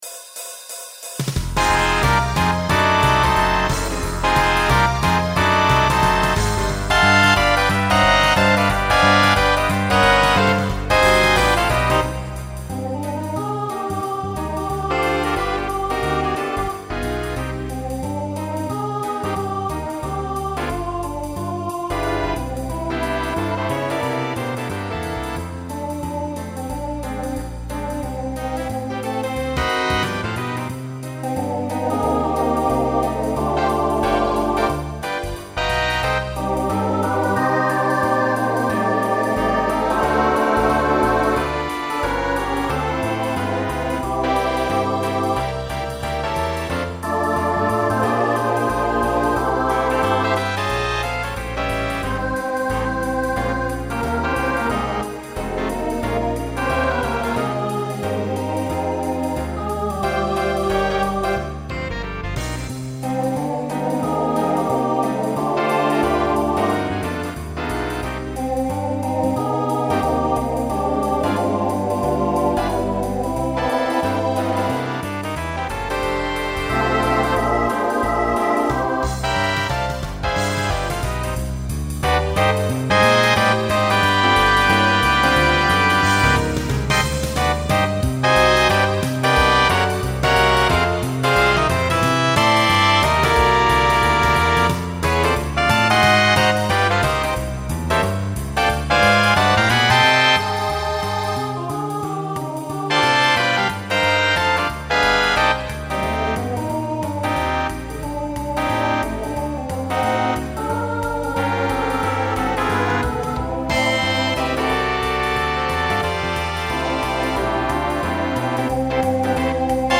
New TTB voicing for 2022.